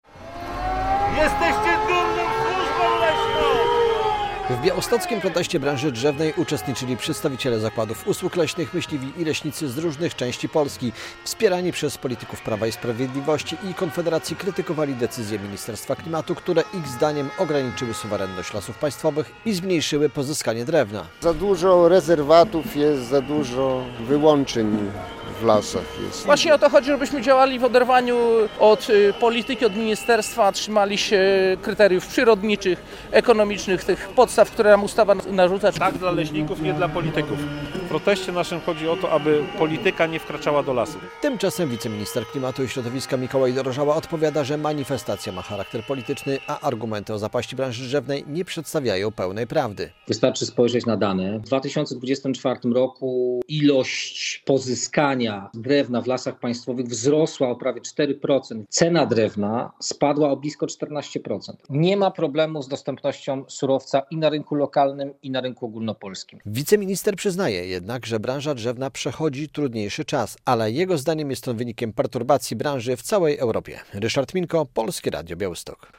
Protest branży drzewnej w Białymstoku - relacja